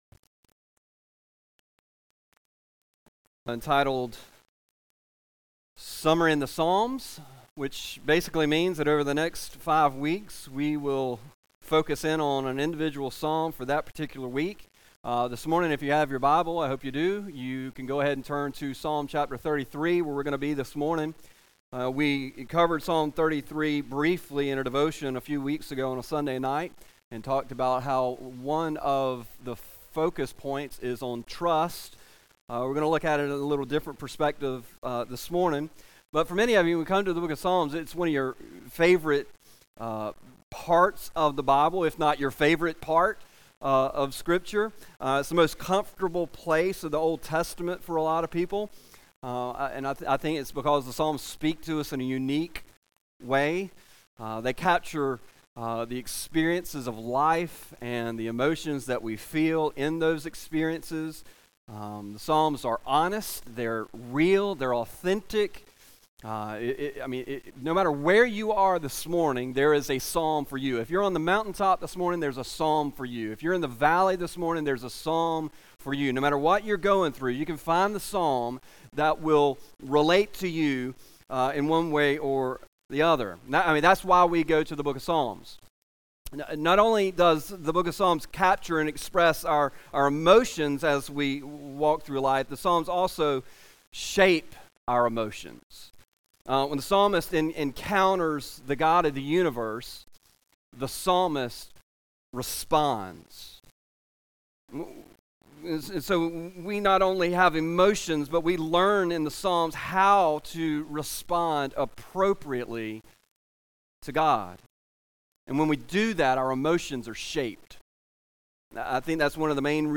A message from the series "Summer in the Psalms."